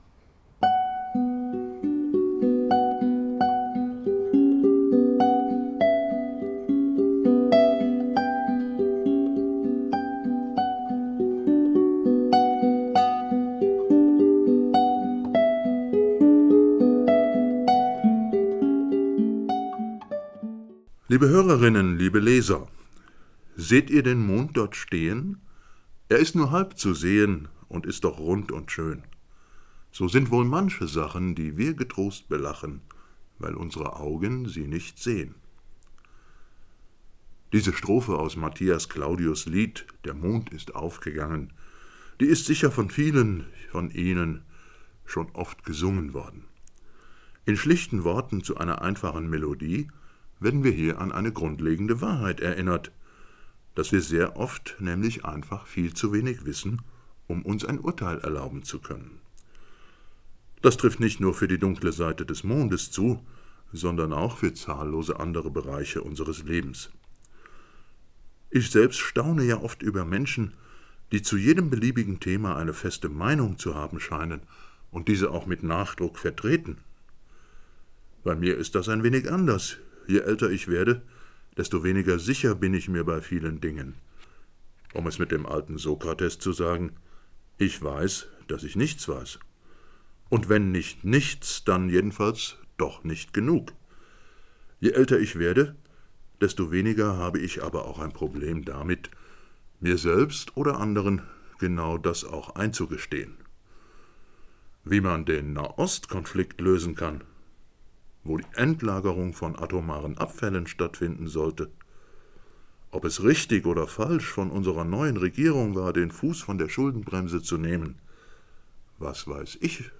Sprach- und Musikaufnahmen entstanden in der Jakobuskirche.